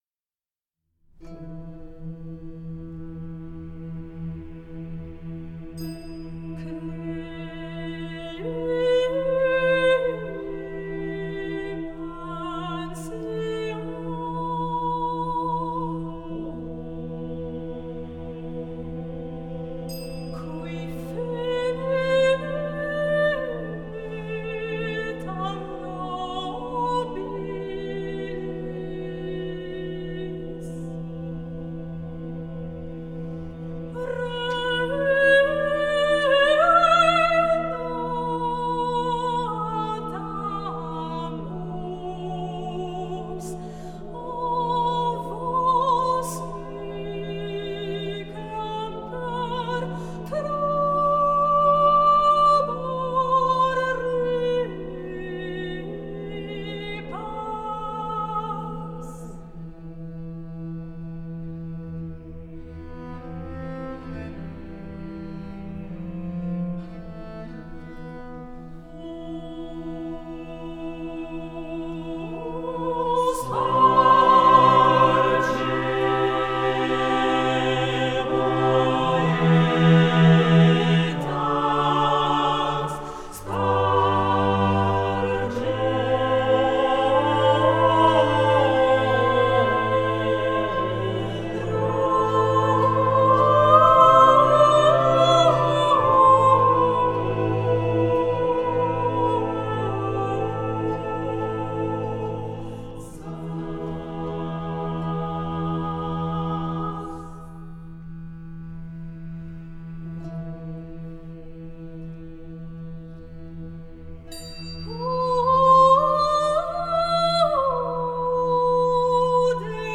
Musique de scène
la musique originale pour chœur et orchestre de chambre